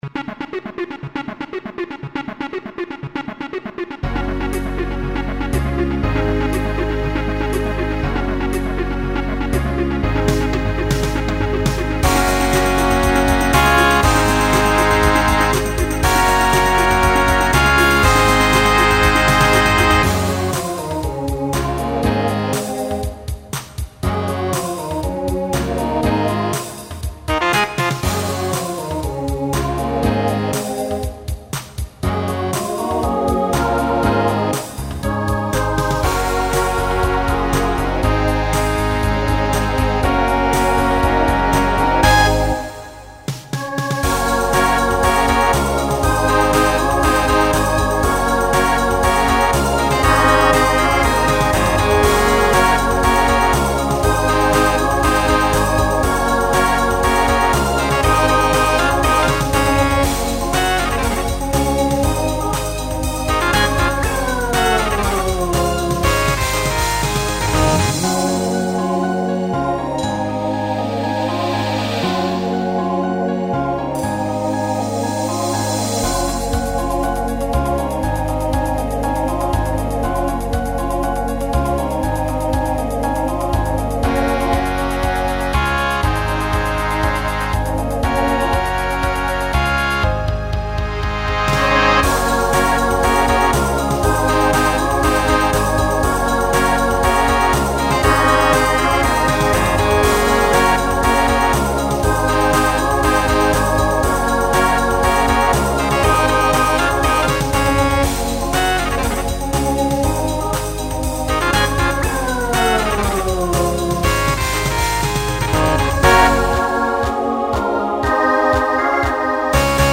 Genre Pop/Dance Instrumental combo
Transition Voicing SATB